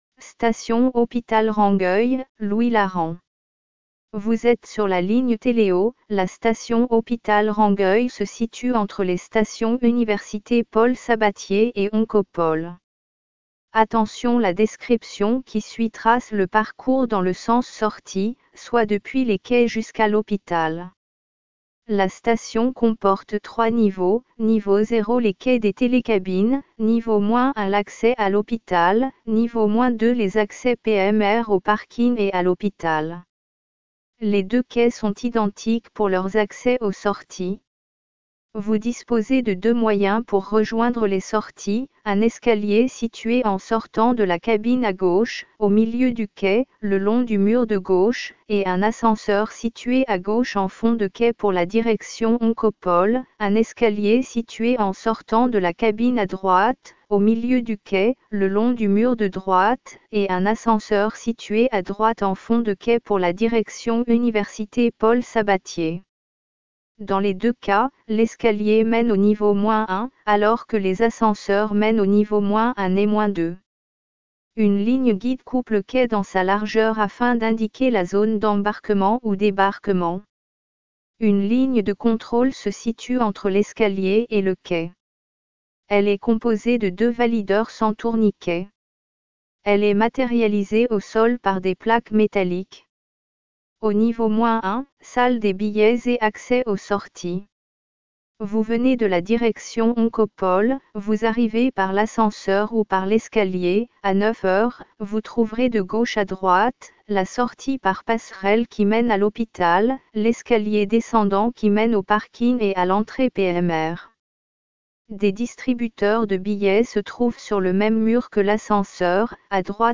Audiodescriptions des stations Téléo | Tisséo